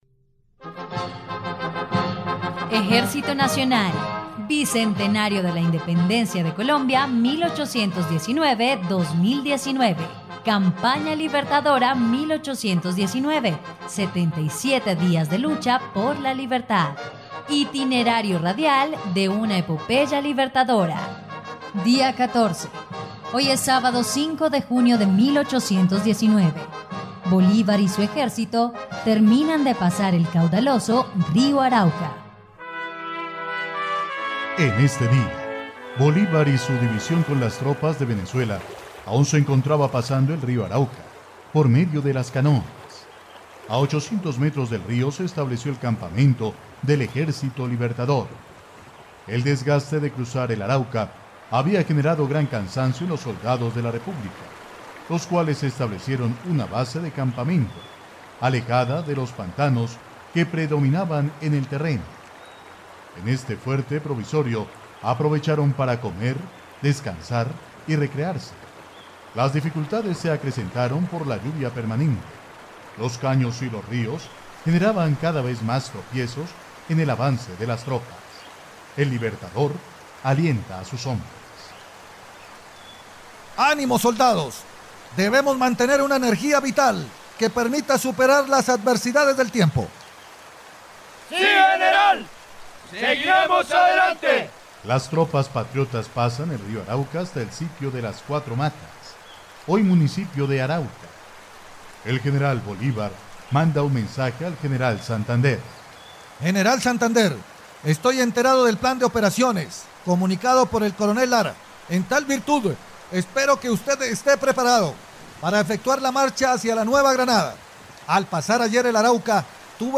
dia_14_radionovela_campana_libertadora.mp3